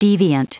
Prononciation du mot deviant en anglais (fichier audio)
Prononciation du mot : deviant